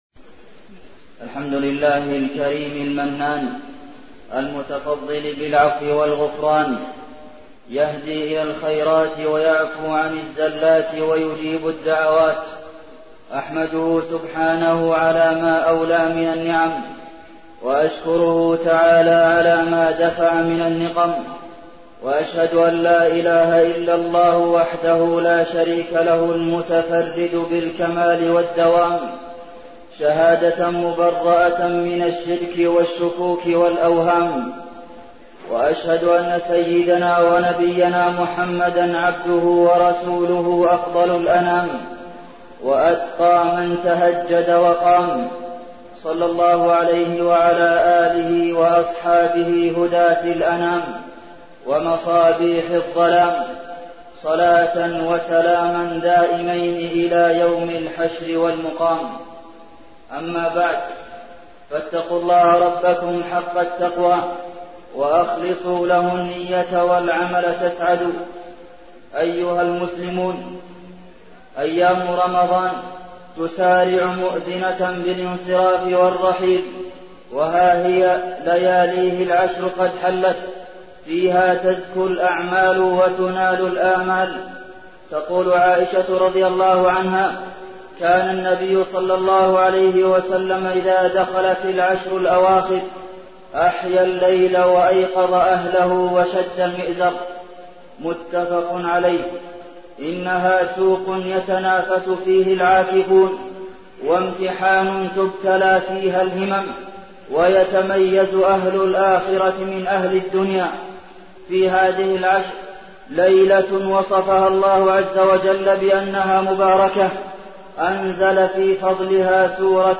تاريخ النشر ٢١ رمضان ١٤١٩ هـ المكان: المسجد النبوي الشيخ: فضيلة الشيخ د. عبدالمحسن بن محمد القاسم فضيلة الشيخ د. عبدالمحسن بن محمد القاسم العشر الأواخر من رمضان